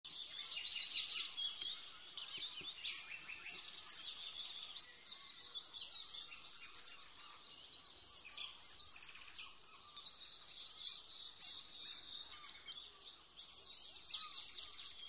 Boomkruiper
Boomkruiper.mp3